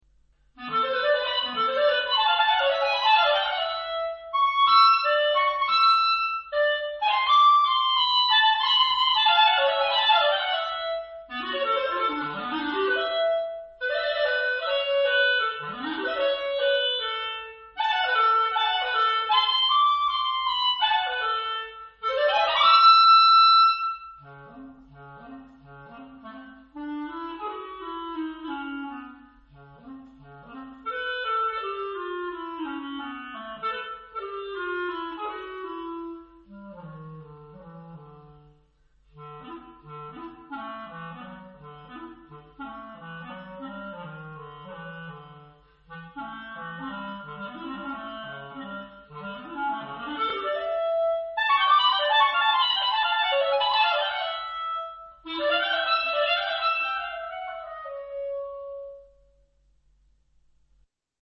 Pièce pour clarinette seule